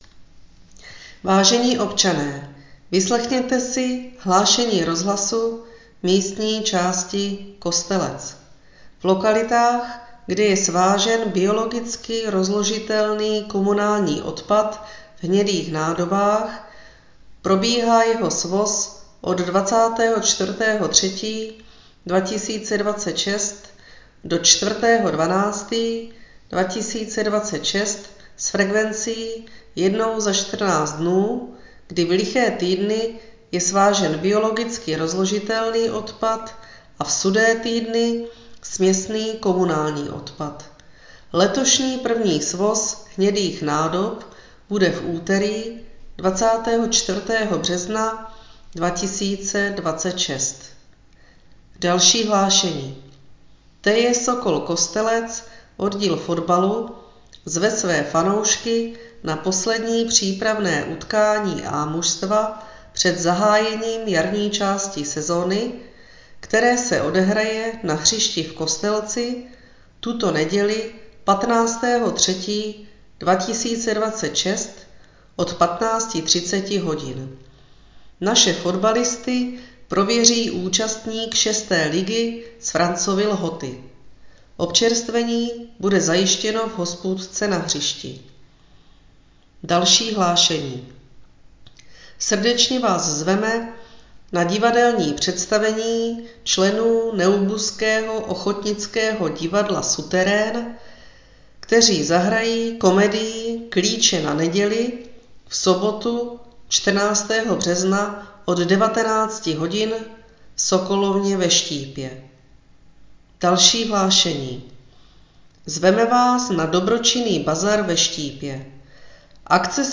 Hlášení místního rozhlasu